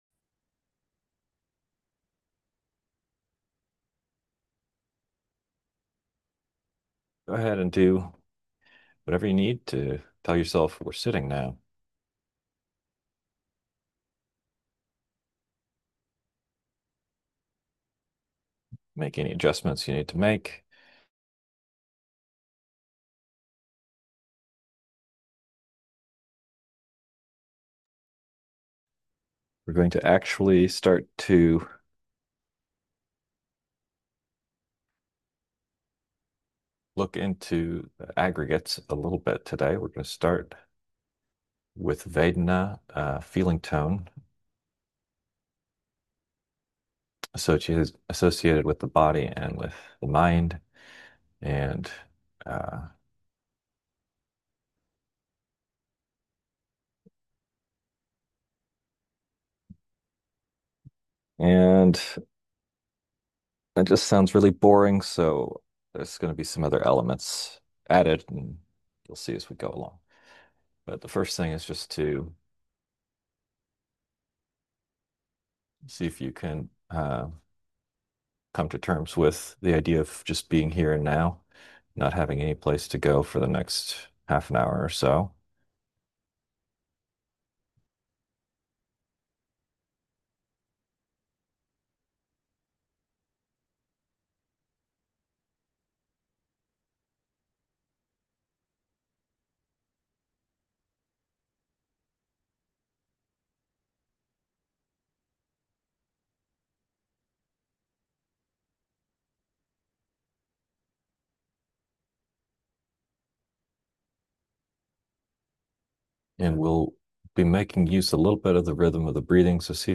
Podcast (guided-meditations): Play in new window | Download